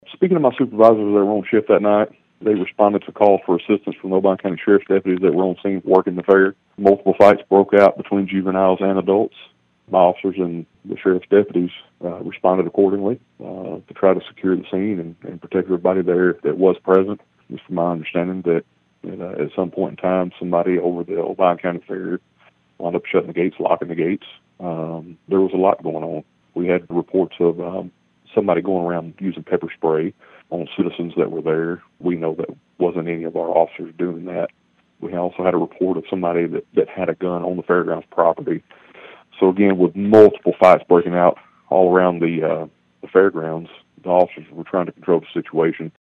Union City Police Chief Ben Yates told Thunderbolt News about his departments call to assist on Saturday night.(AUDIO)